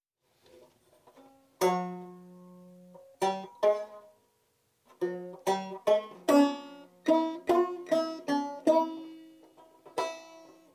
Plucked String (00:10)
Plucked String.wav